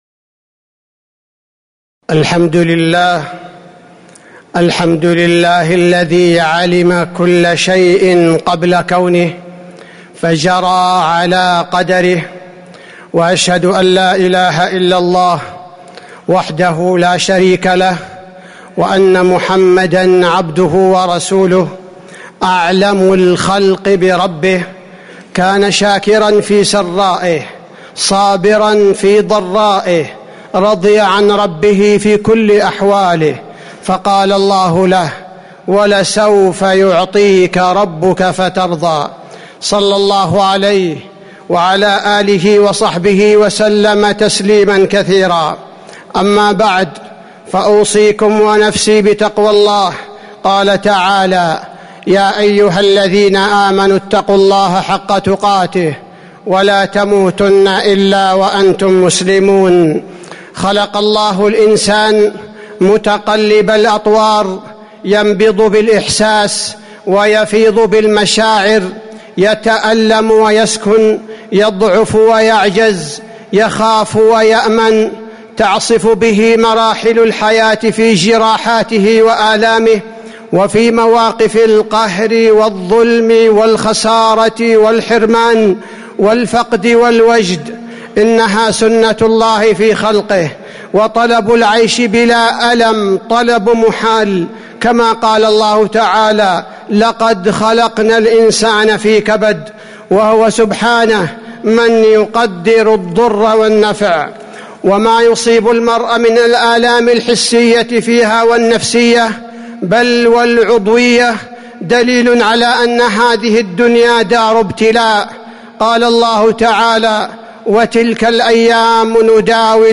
تاريخ النشر ١٧ جمادى الأولى ١٤٤٥ هـ المكان: المسجد النبوي الشيخ: فضيلة الشيخ عبدالباري الثبيتي فضيلة الشيخ عبدالباري الثبيتي الألم فوائده وعلاجه